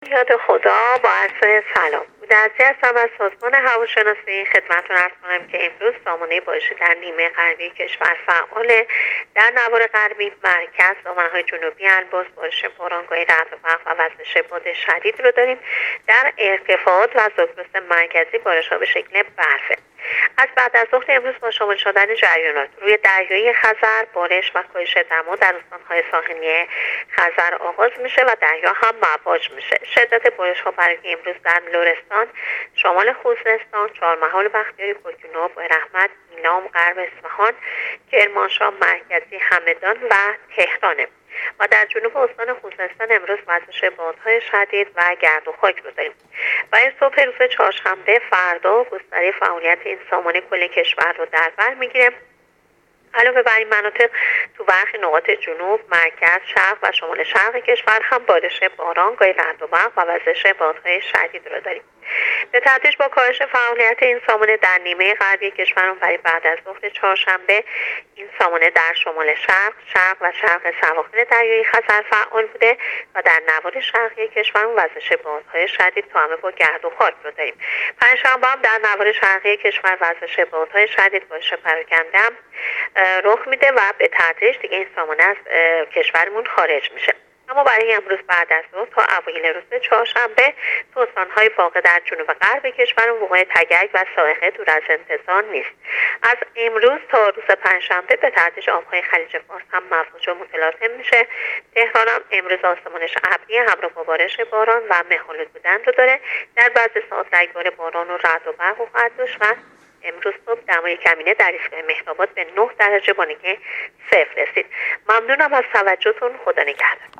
گزارش رادیو اینترنتی از آخرین وضعیت آب و هوای ۶ اسفندماه ۱۳۹۸